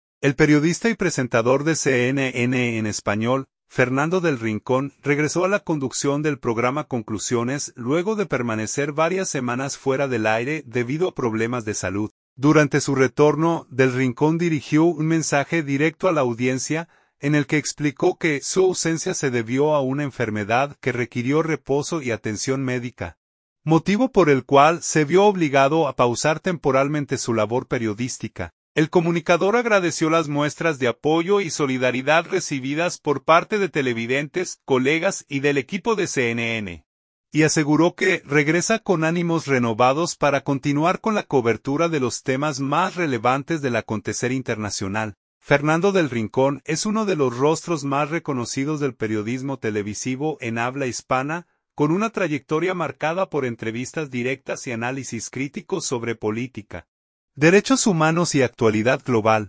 Durante su retorno, Del Rincón dirigió un mensaje directo a la audiencia, en el que explicó que su ausencia se debió a una enfermedad que requirió reposo y atención médica, motivo por el cual se vio obligado a pausar temporalmente su labor periodística.